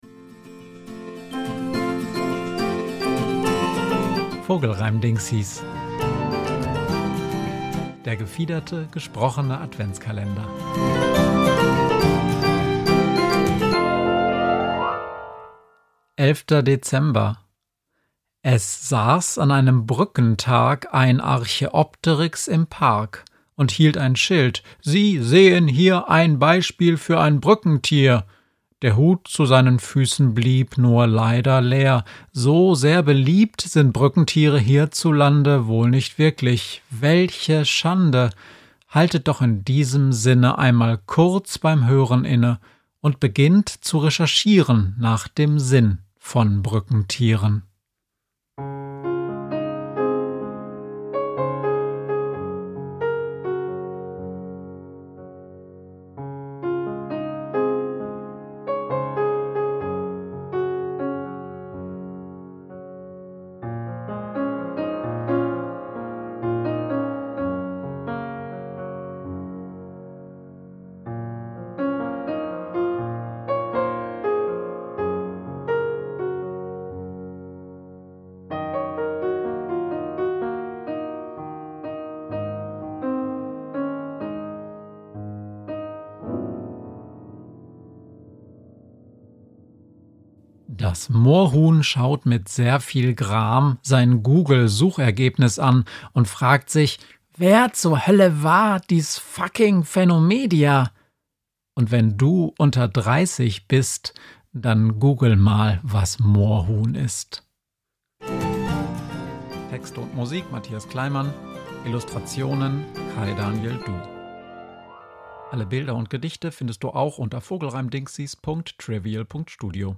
Vogelreimdingsis ist der gefiederte, gesprochene Adventskalender